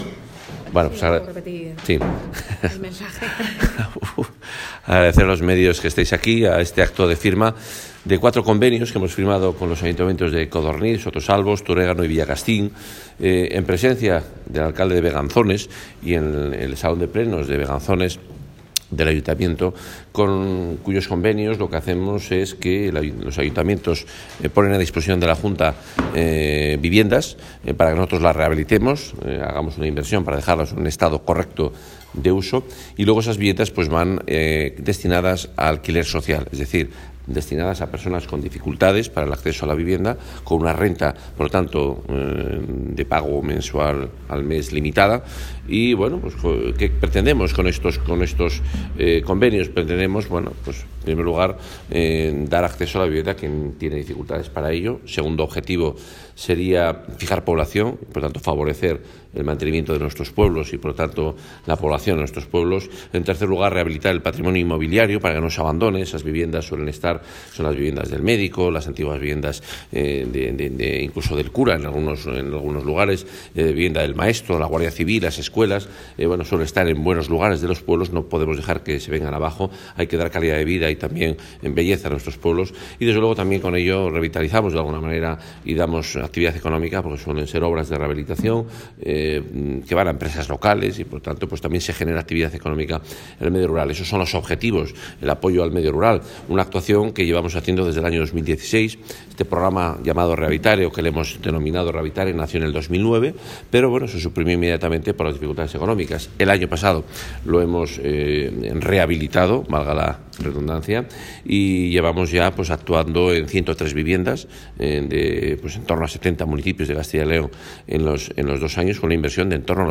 Declaraciones del consejero de Fomento y Medio Ambiente.